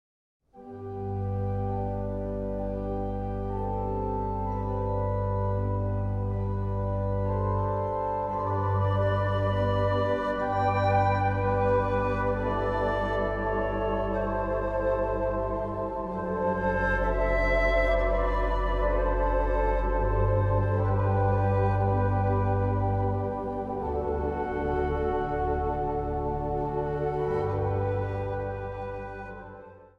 Instrumentaal | Dwarsfluit
Instrumentaal | Harp
Instrumentaal | Hobo
Instrumentaal | Panfluit
Instrumentaal | Saxofoon
Instrumentaal | Synthesizer